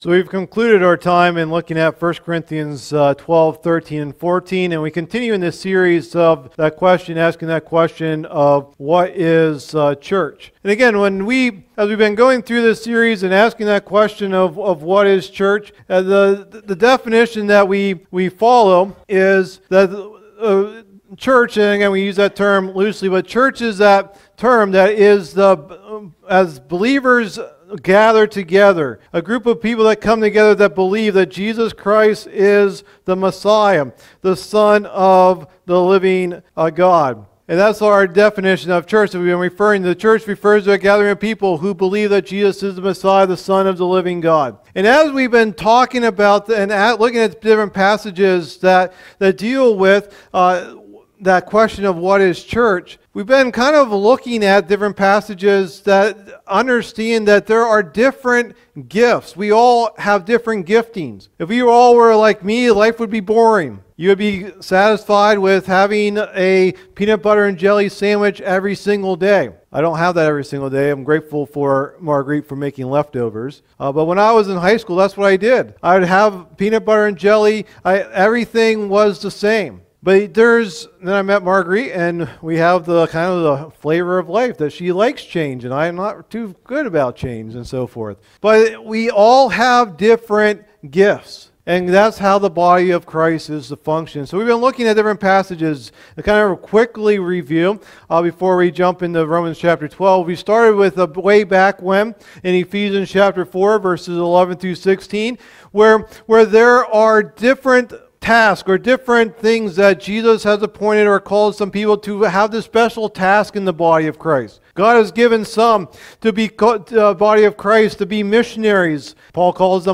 Download Download Reference Romans 12:3-8 Sermon Notes Romans 12.3-8.pdf Message #15 in the "What is Church?" teaching series What is "Church"?